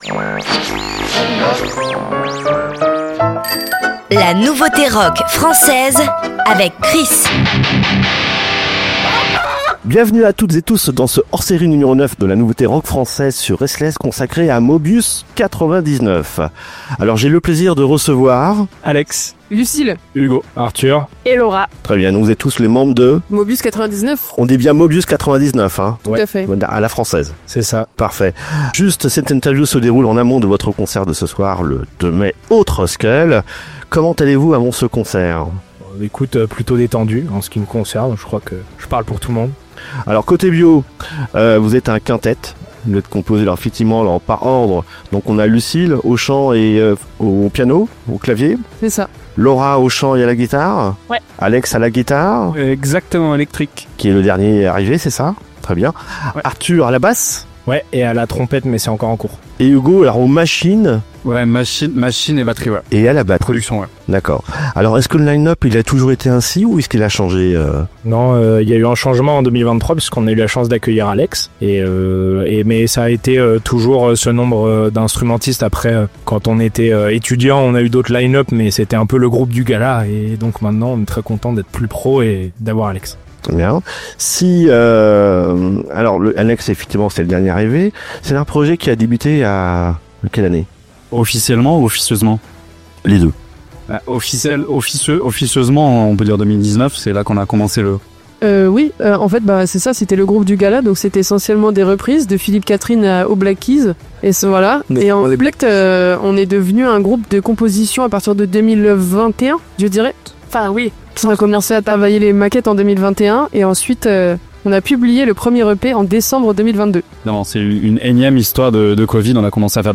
Ce quintet doué et talentueux dont les voix nous font frissonner, nous émeuvent et surtout nous font danser, nous a fait l’honneur de se confier dans une interview exclusive...